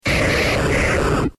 Cri d'Électrode dans Pokémon X et Y.